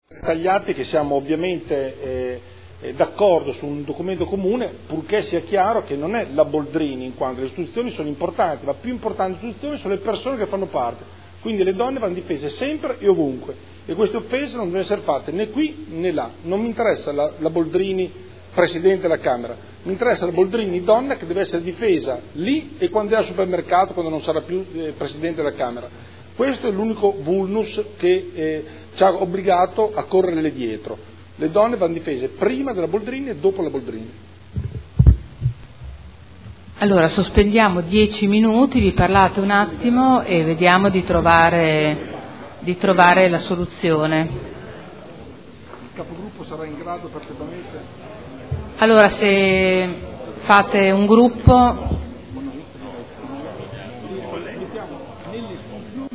Andrea Galli — Sito Audio Consiglio Comunale
Seduta del 27/03/2014. Dibattito su Ordine del Giorno presentato dal gruppo consiliare SEL per condannare ogni espressione sessista e ogni forma di discriminazione di genere usata come arma di battaglia politica e per respingere il clima di incitamento all’odio sessista che ha colpito tutte le donne presenti nelle istituzioni, e Ordine del Giorno presentato dai consiglieri Galli, Taddei, Morandi, Bellei (Forza Italia – PdL), Celloni (Movimento per Cambiare – Insieme per Modena), avente per oggetto: “Condannare ogni espressione sessista e ogni forma di discriminazione di genere usata come arma di battaglia politica e per respingere il clima di incitamento all’odio sessista che ha colpito tutte le donne presenti nelle istituzioni”